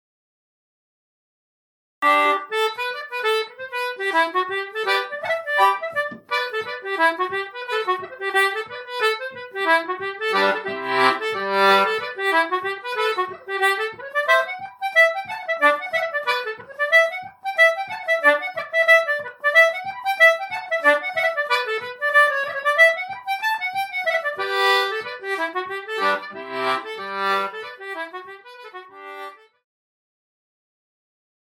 Concertina.mp3